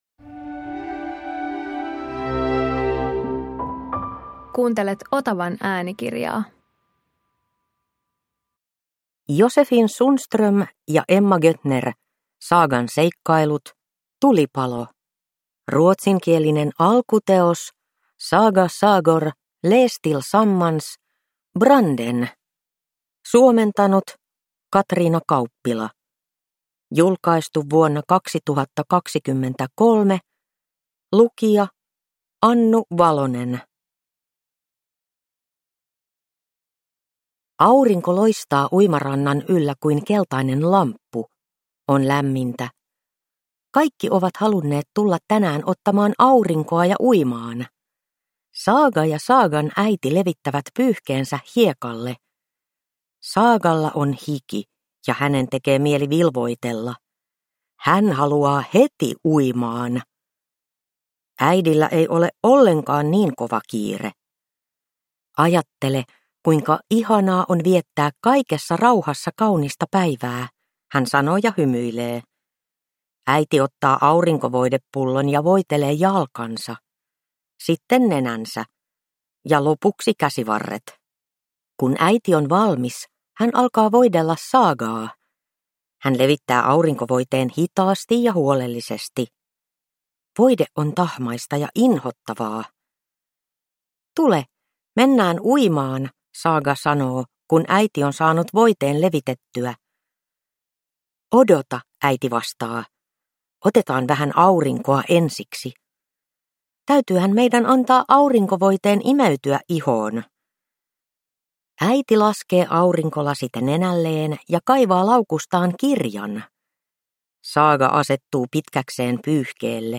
Saagan seikkailut. Tulipalo – Ljudbok